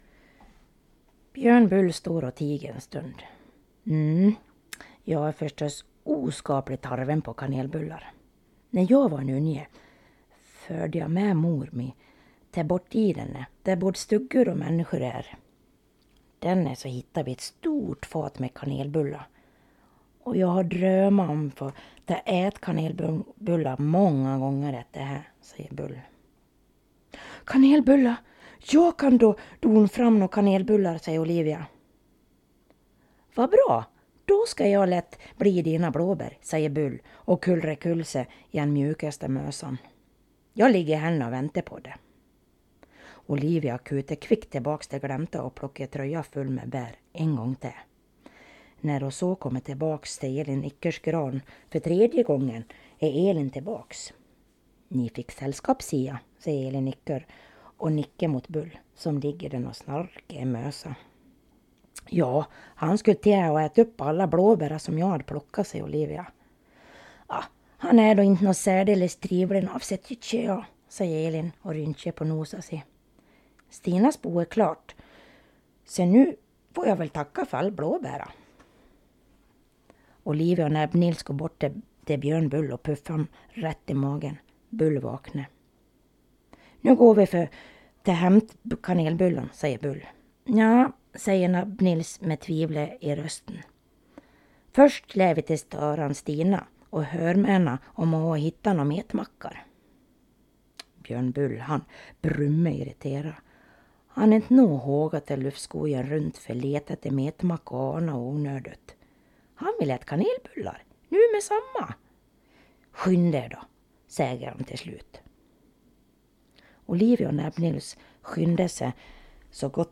Utöver detta kan du lyssna på sagan – både på standardsvenska och bjursmål.
Station 6 – bjursmål
Station06_bjursmal.mp3